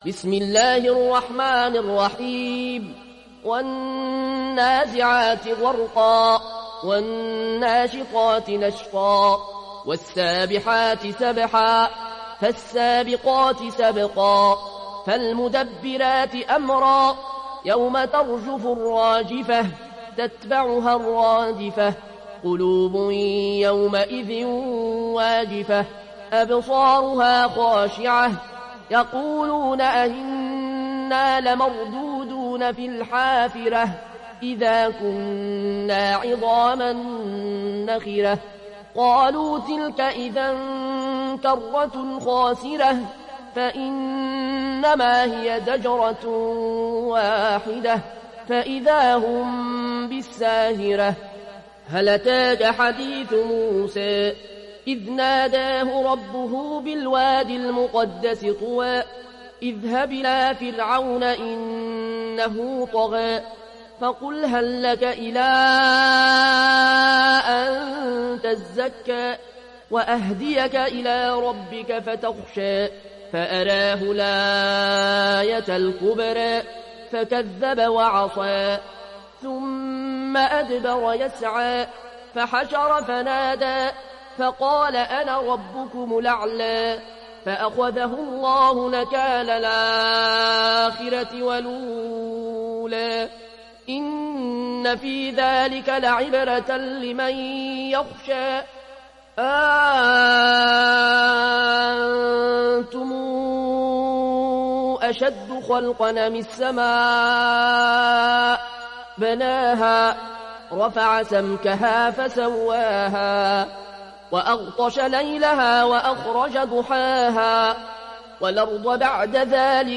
Naziat Suresi İndir mp3 Al Ayoune Al Koshi Riwayat Warsh an Nafi, Kurani indirin ve mp3 tam doğrudan bağlantılar dinle